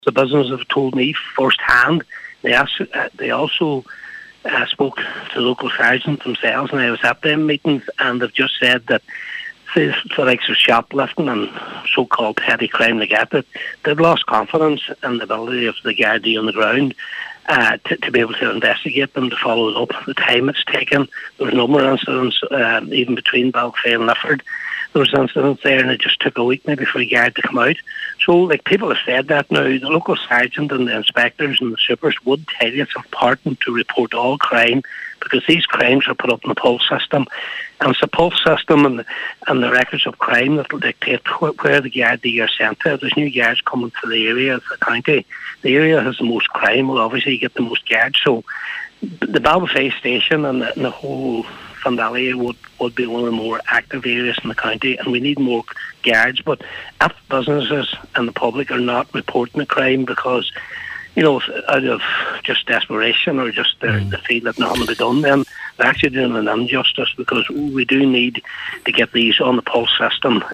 Speaking on the Nine til Noon Show today